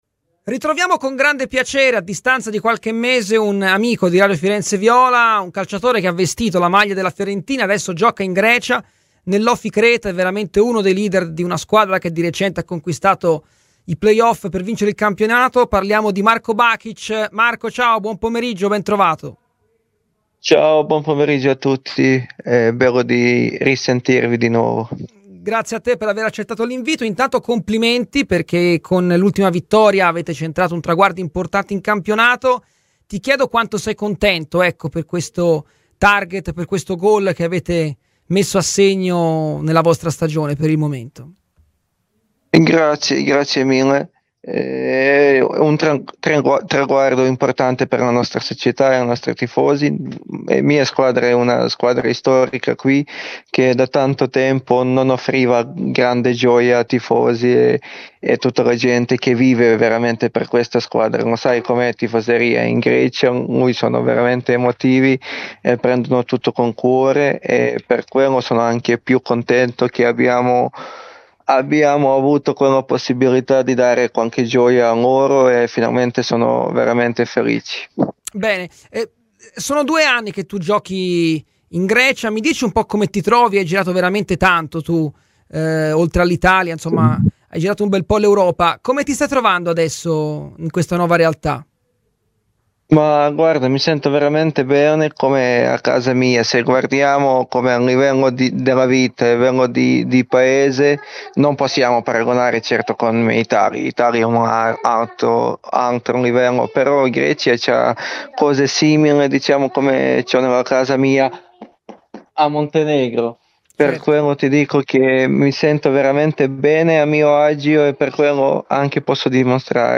intervistato in esclusiva da Radio FirenzeViola